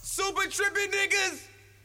SouthSide Chant (18)(1).wav